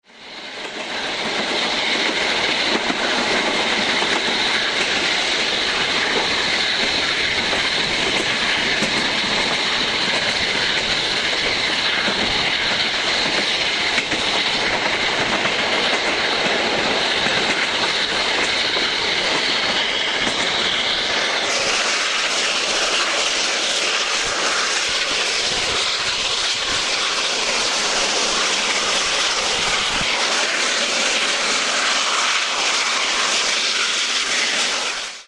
This audio clip, in MP3 format, is taken from a video soundtrack recorded on the train. 141R 1199 can be heard accelerating away from Nevers.